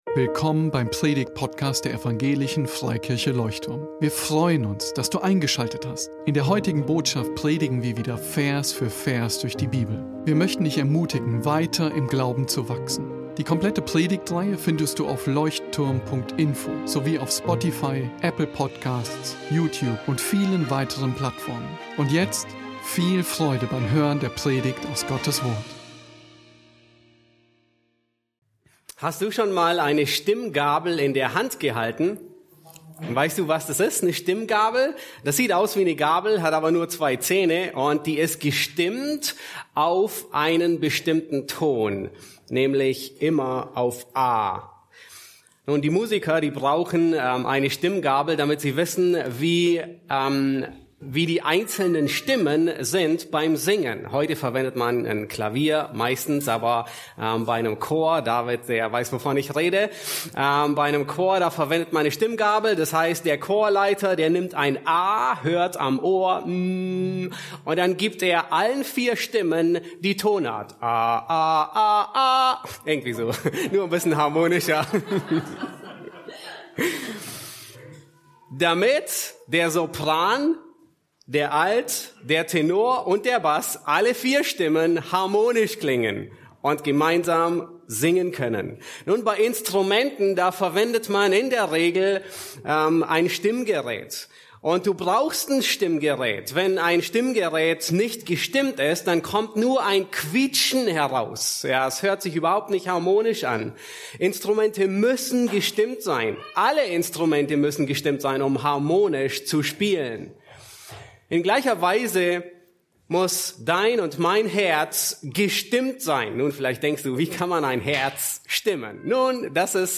Predigtpodcast